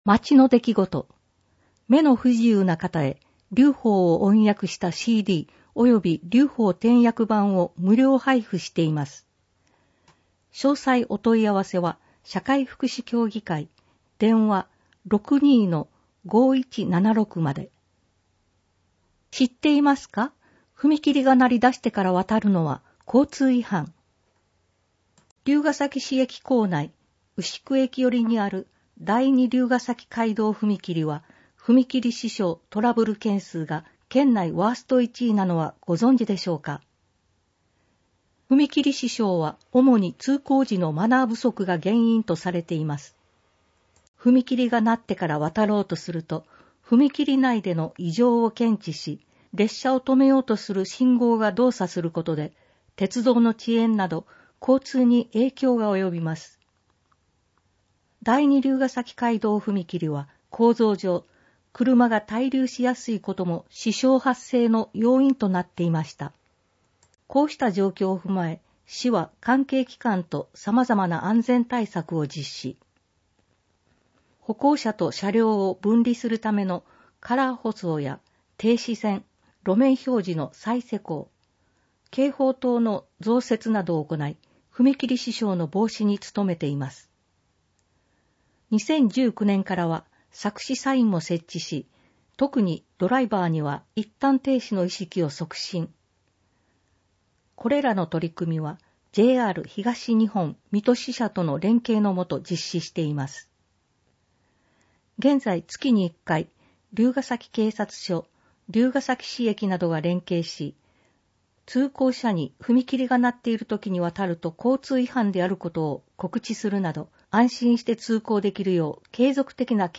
音訳データダウンロード
※MP3データは「龍ケ崎朗読の会」のご協力により作成しています。